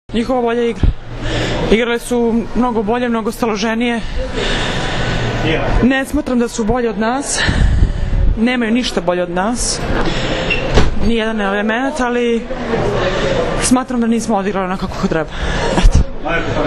IZJAVA STEFANE VELJKOVIĆ